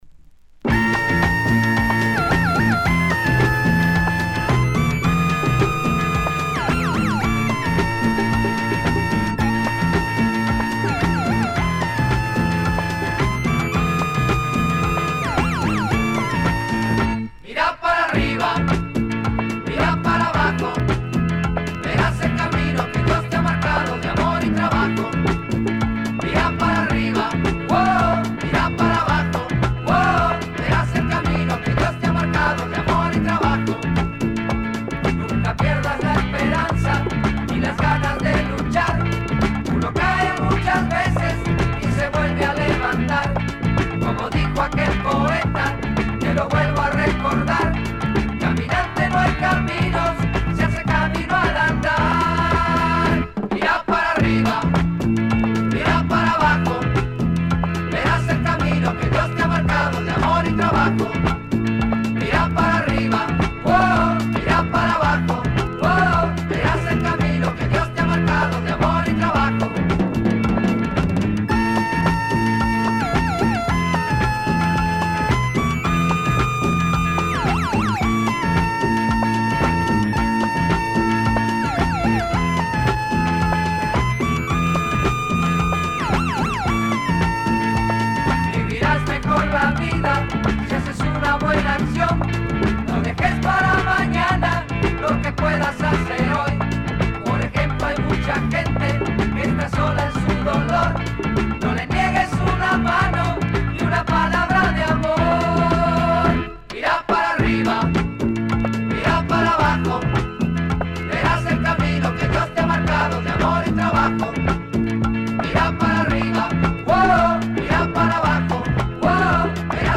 a few great bubble-gum flavored numbers